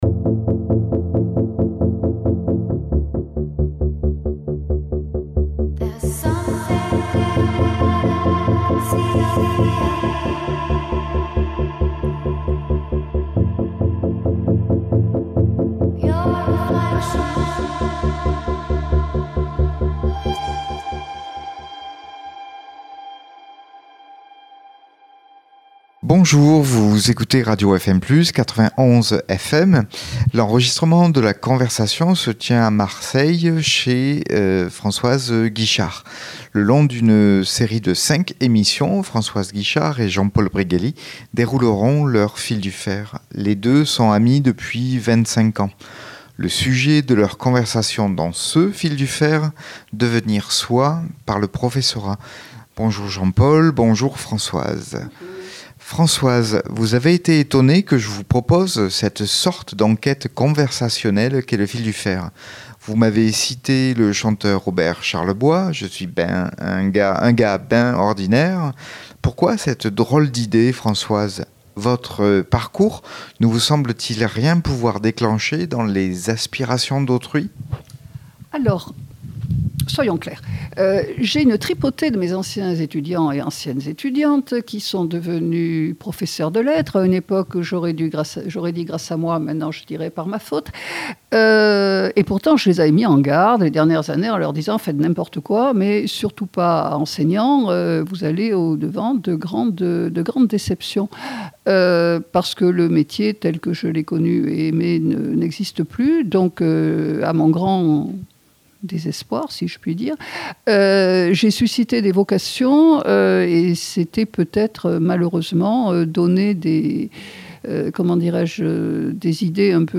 EPISODE 2/5: Bonjour, vous écoutez Radio FM Plus – 91 FM. L’enregistrement de la conversation se tient à Marseille dans l’appartement d’une de nos deux invités.